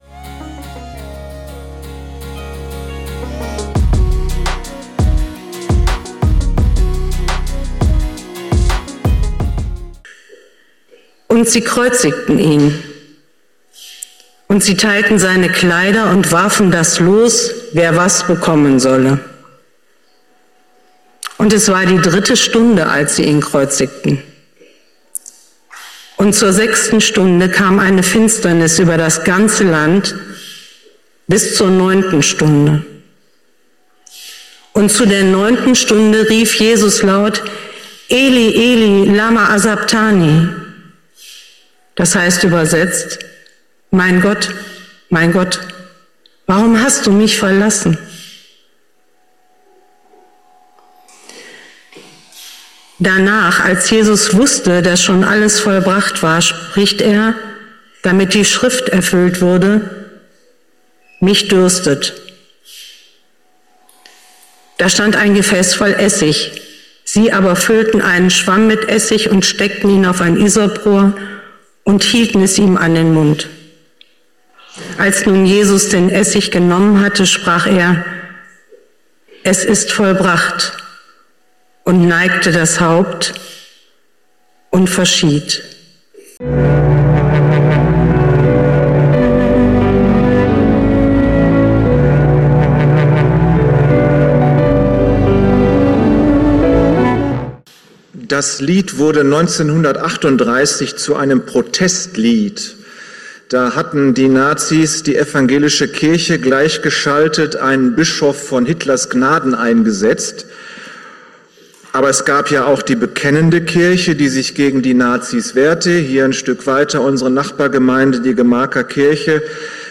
Höre dir das Lied an, bevor du die Predigt hörst.